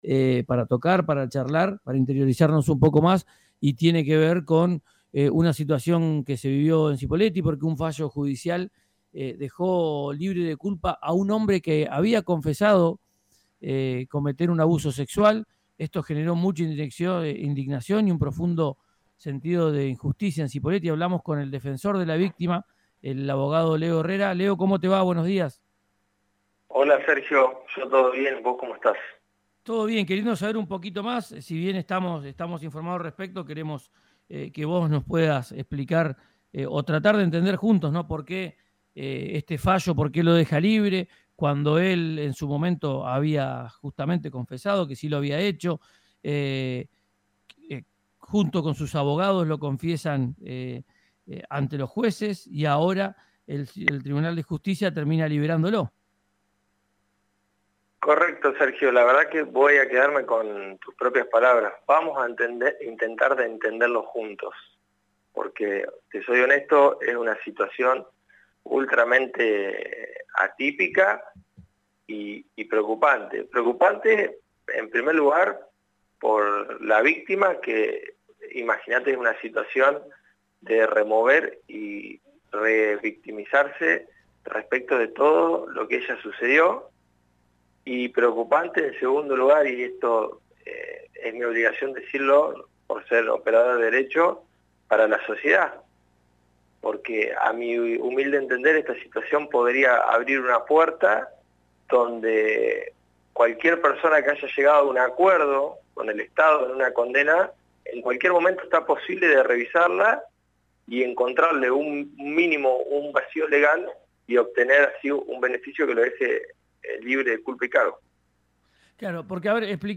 En diálogo con RÍO NEGRO RADIO se mostró preocupado ante esta actualización en la causa.